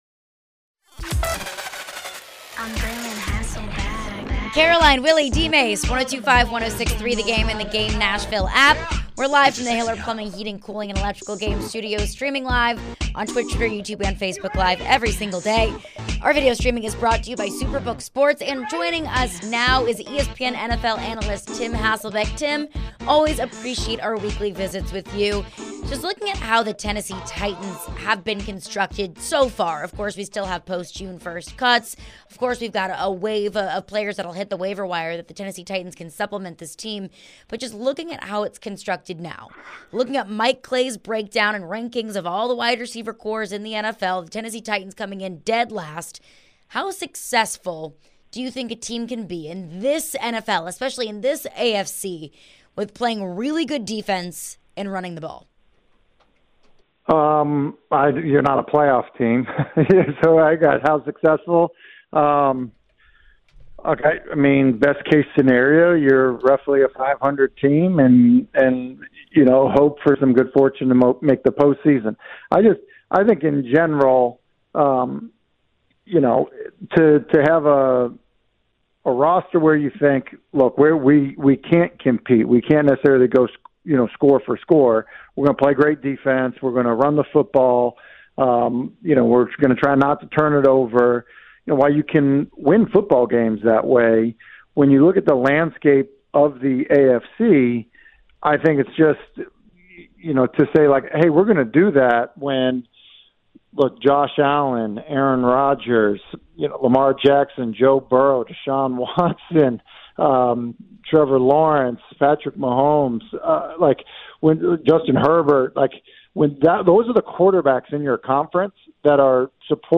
ESPN NFL Analyst Tim Hasselbeck joined for his weekly visit to discuss the Titans, rookie quarterbacks and more.